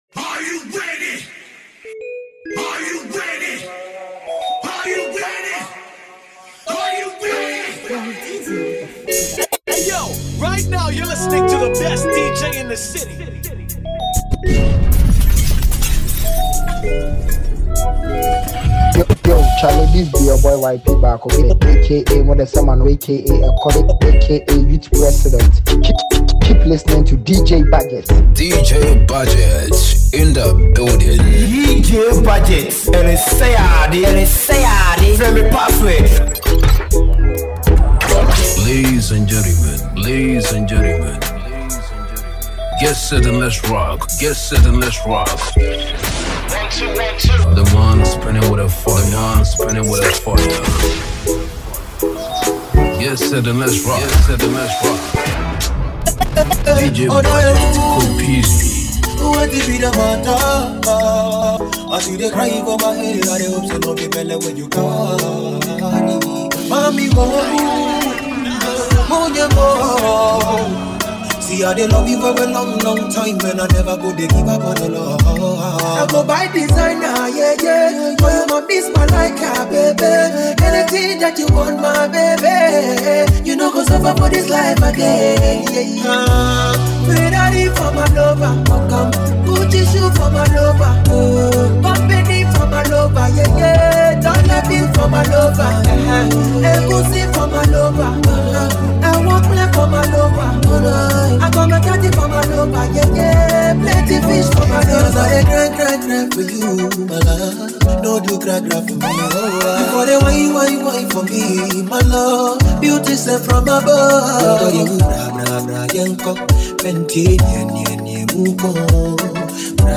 music compilation